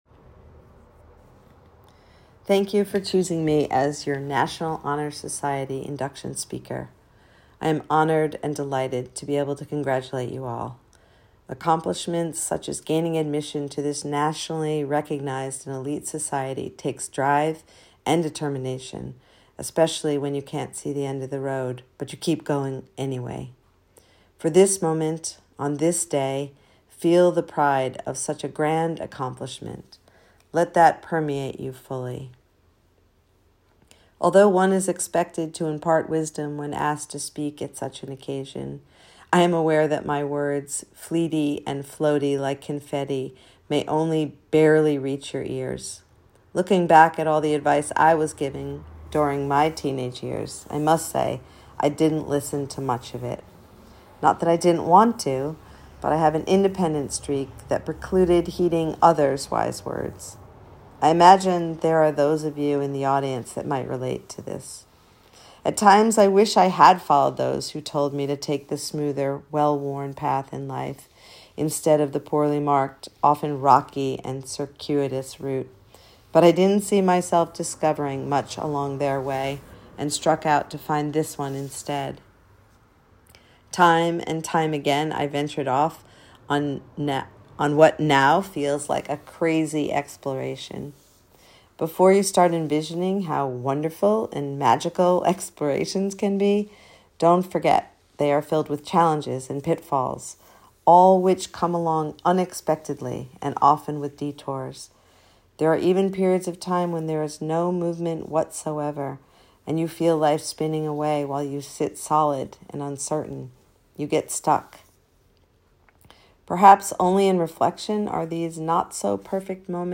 This blog post is my speech, (at the end of it I include an audio of myself reading it because speeches are meant to be heard.)
induction-speech.m4a